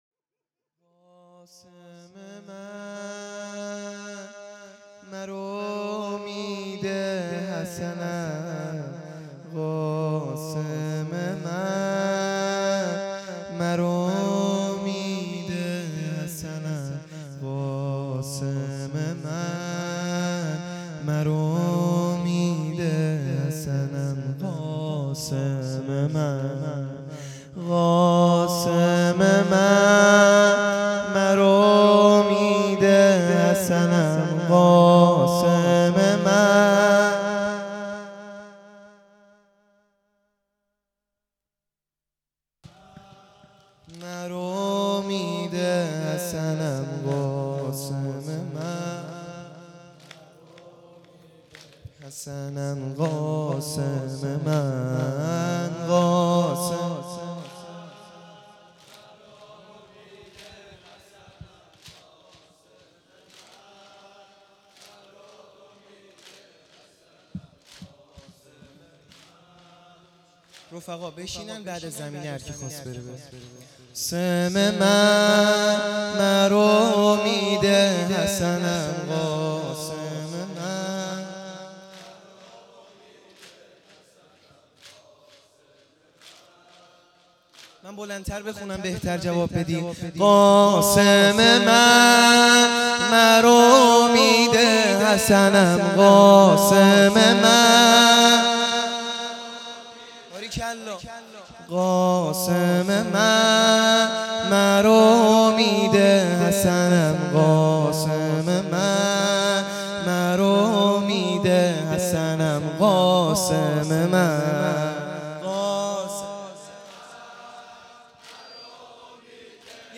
زمینه | قاسم من مرو امید حسنم